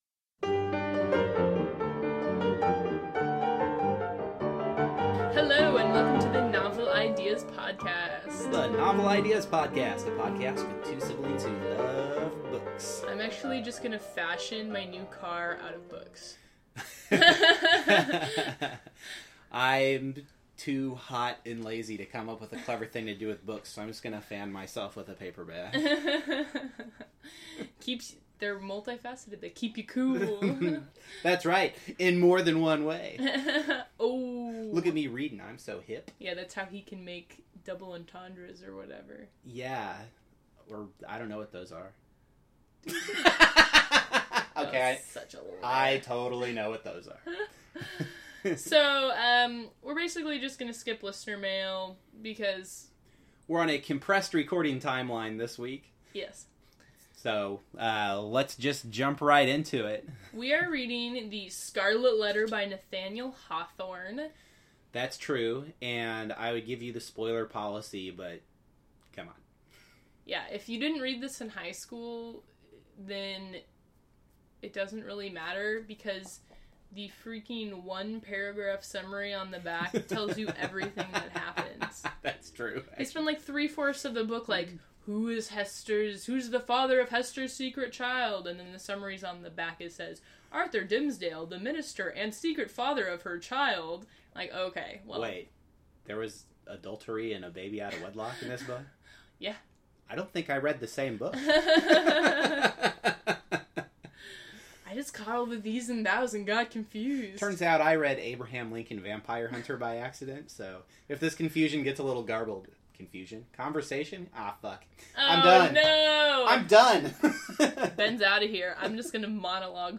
The music bump is Domenico Scarlatti’s Sonata in G minor, K. 108.